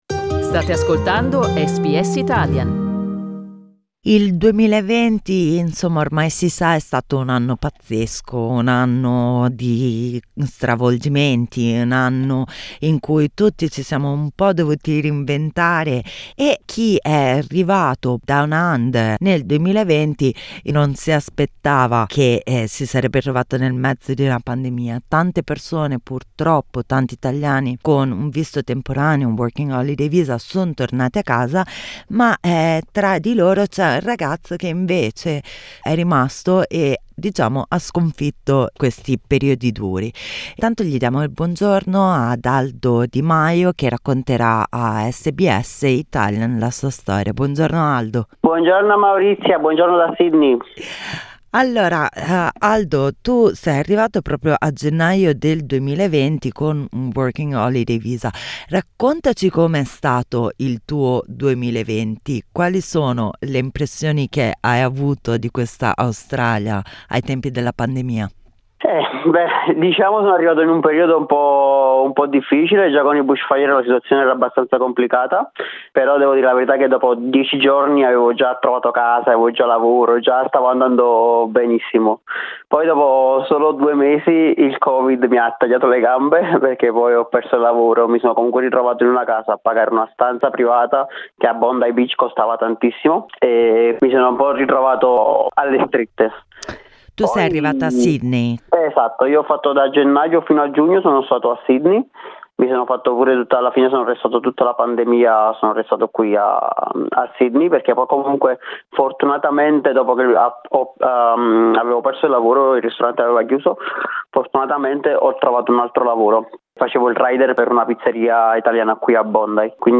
I primi mesi della pandemia sono stati “i più difficili”, ha ammesso in un'intervista con SBS Italian.